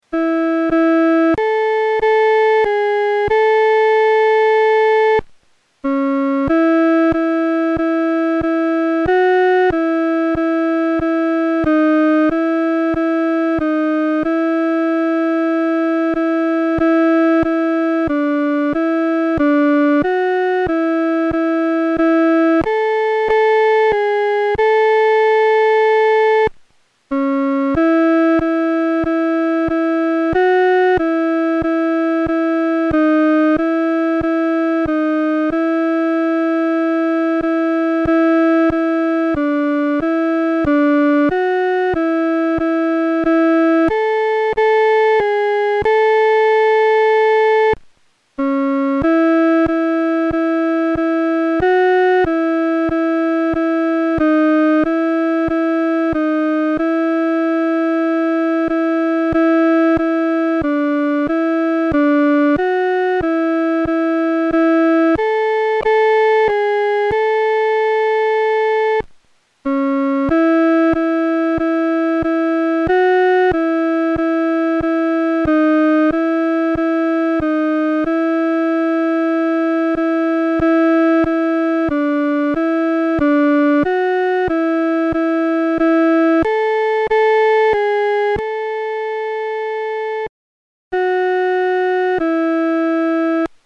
伴奏
女低